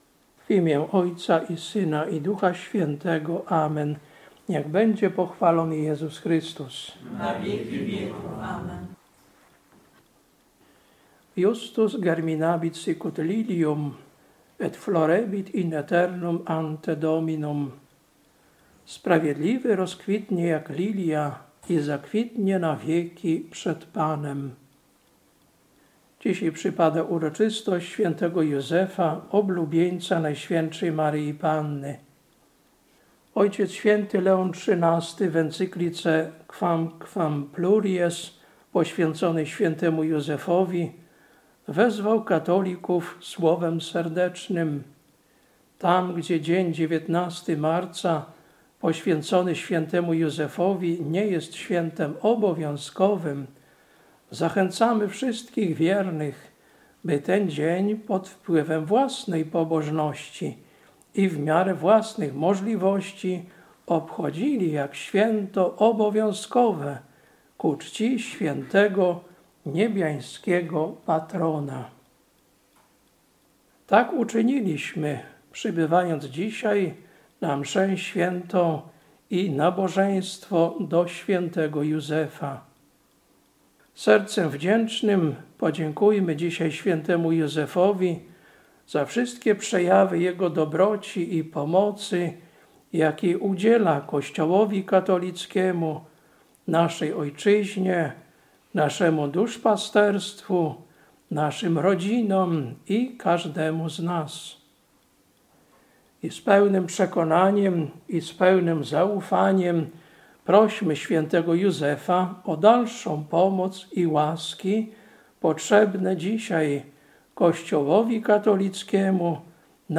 Kazanie na uroczystość św. Józefa, Oblubieńca NMP, 19.03.2025